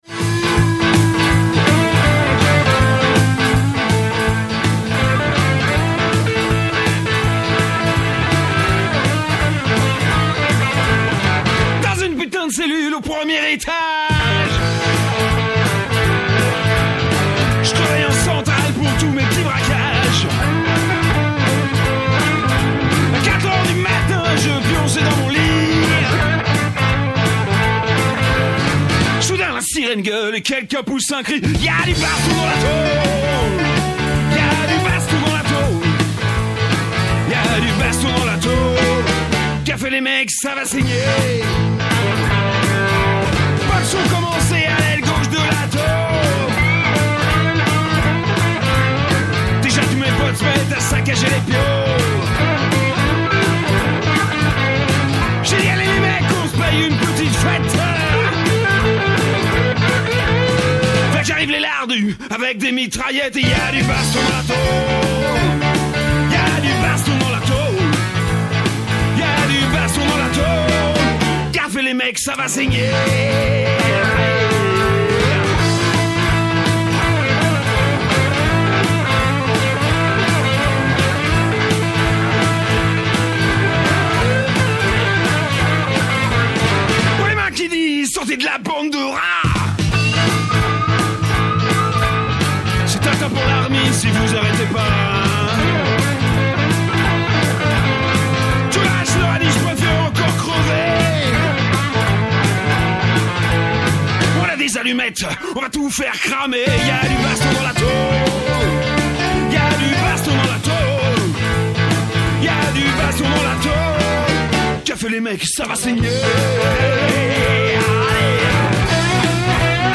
Elle donne la parole aux prisonniers, prisonnières et leurs proches & entretient un dialogue entre l’intérieur et l’extérieur des prisons.
Émission de l’Envolée du vendredi 4 octobre 2024 L’Envolée est une émission radio pour en finir avec toutes les prisons.